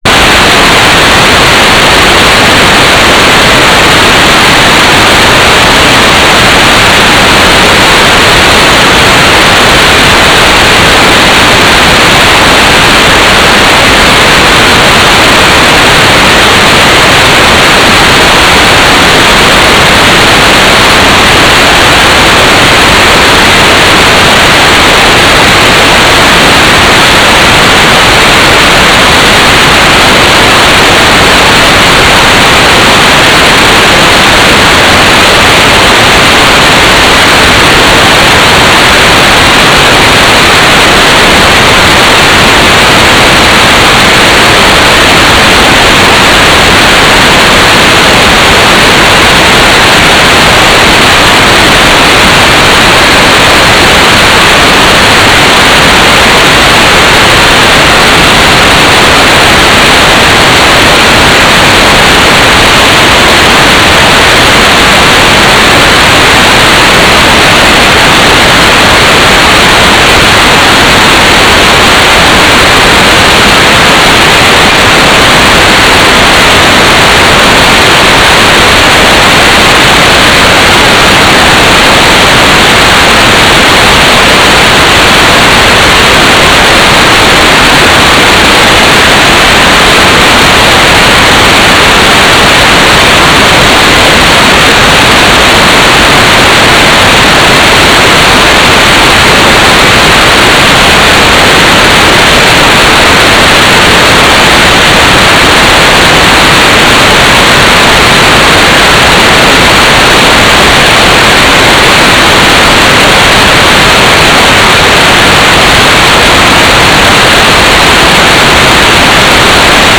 "transmitter_mode": "MSK AX.100 Mode 5",